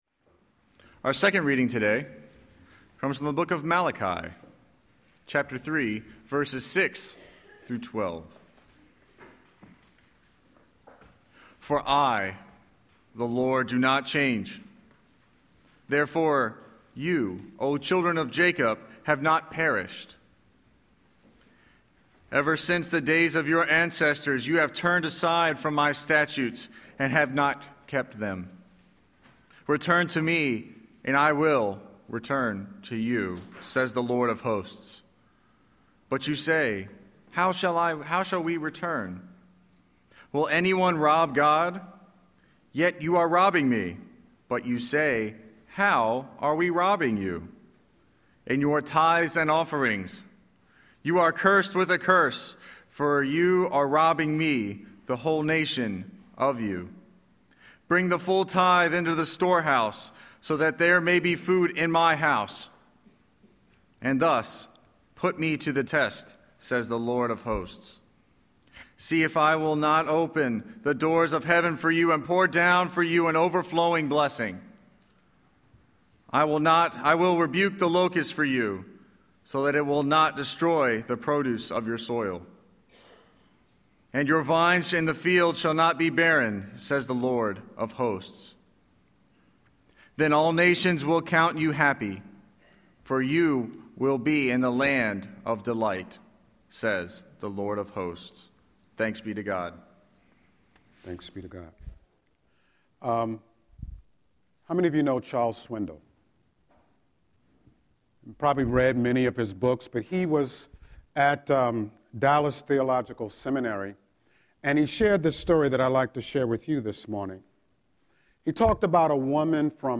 11-15-Scripture-and-Sermon.mp3